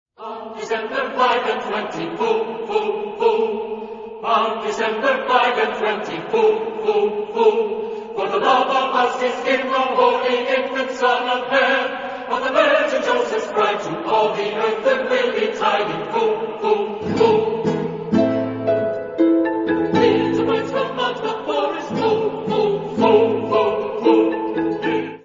Genre-Style-Forme : Profane ; Sacré ; noël ; Cycle
Caractère de la pièce : rythmé
Type de choeur : SATB  (4 voix mixtes )
Instrumentation : Piano OU Harpe
Tonalité : mineur